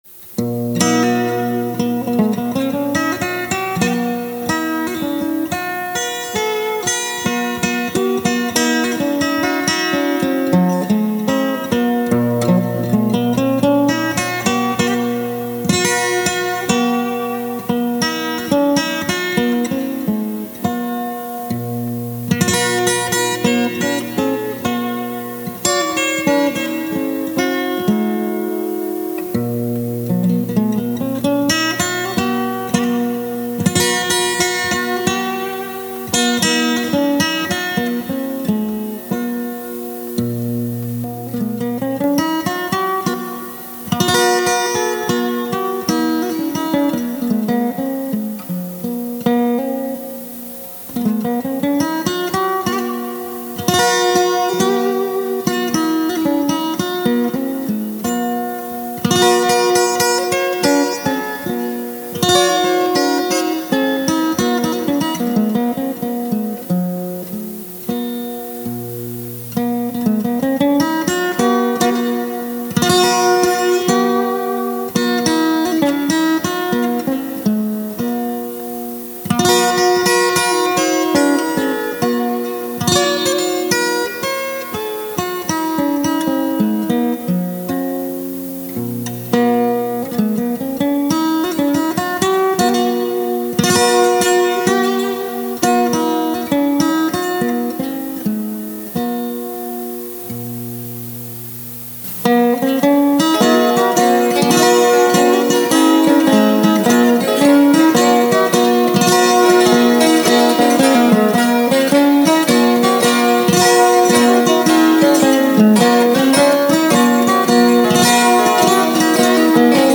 ‘Carpentry’ is an instrumental version of ‘The Carpenter’s Son’. The song was originally intended to be sung unaccompanied, but it somehow developed a guitar accompaniment with a slight Middle Eastern/North African/desert lute feel, and the first section of the instrumental is very much based on that.
The faster second section was meant to sound more medieval, and includes  overdubbed dulcimer and bouzouki.